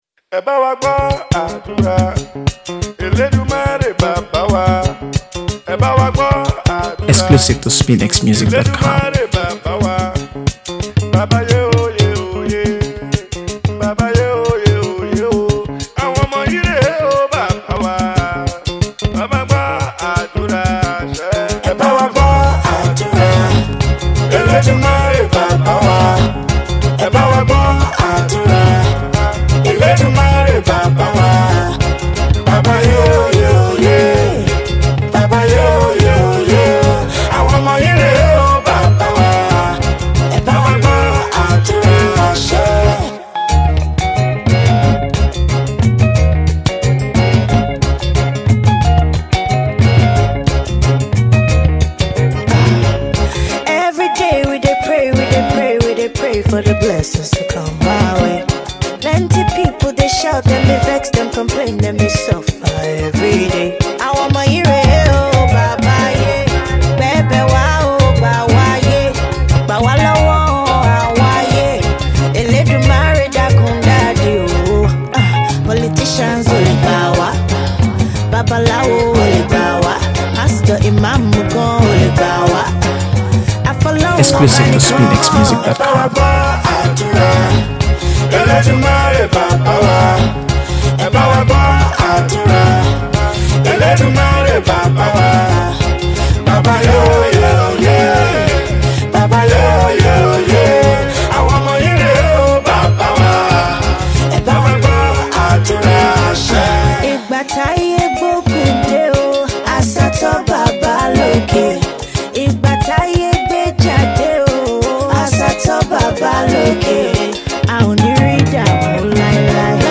AfroBeats | AfroBeats songs
offering an unforgettable blend of melody and emotion.